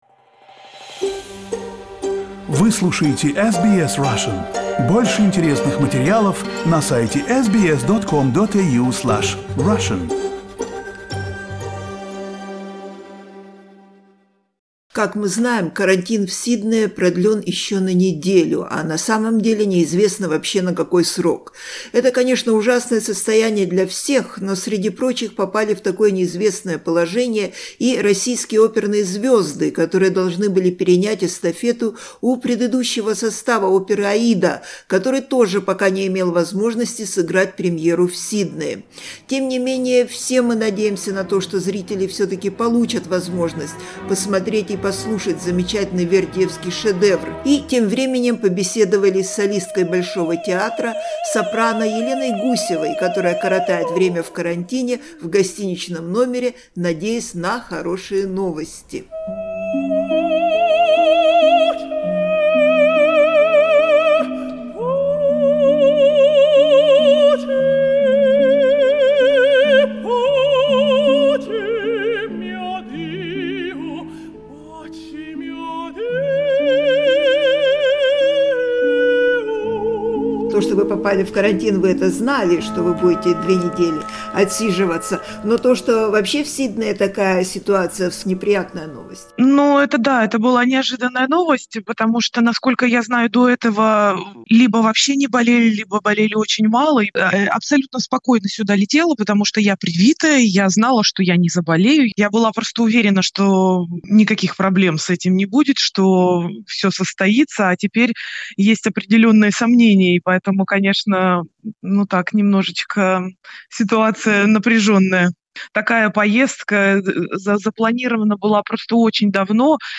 Russian opera stars in Sydney: interview